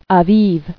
[A·bib]